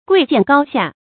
贵贱高下 guì jiàn gāo xià 成语解释 指人的社会地位高低不同。